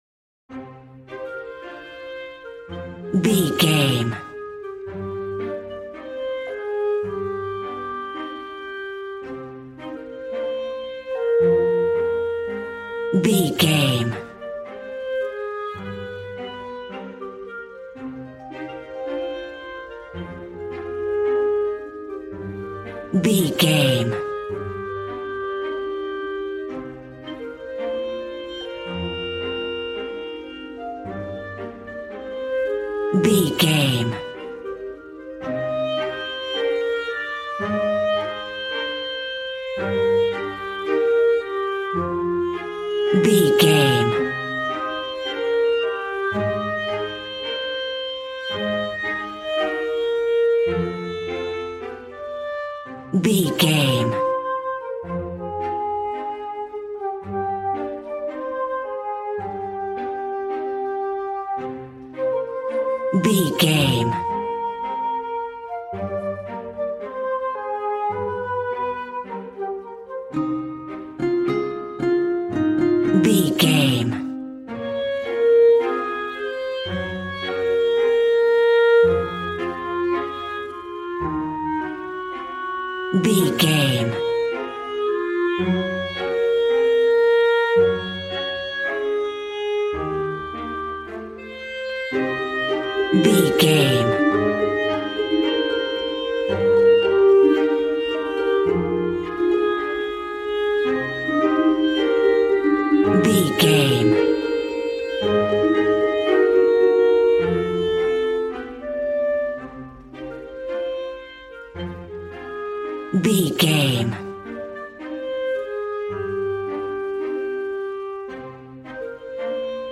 A warm and stunning piece of playful classical music.
Regal and romantic, a classy piece of classical music.
Ionian/Major
E♭
regal
piano
violin
strings